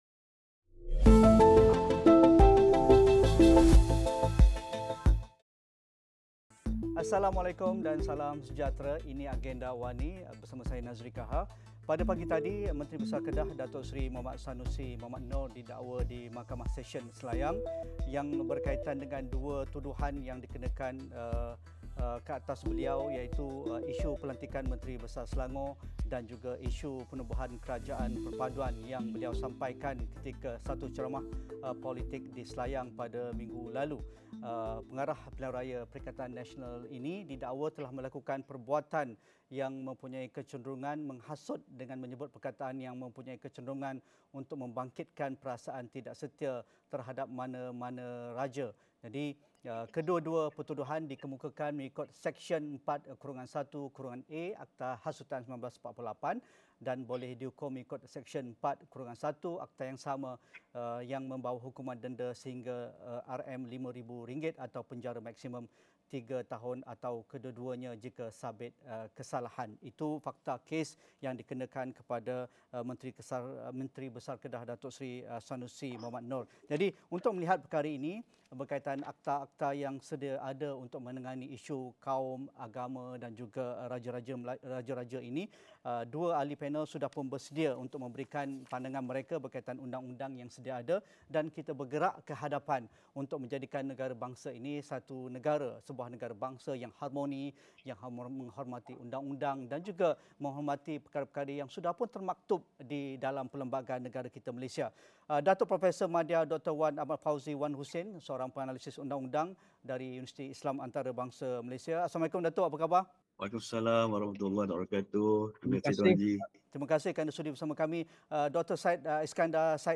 Adakah undang-undang sedia ada tidak mencukupi untuk menangani sebarang ancaman provokasi yang melampau yang menjejaskan keharmonian dan perpaduan di negara ini? Diskusi 8.30 malam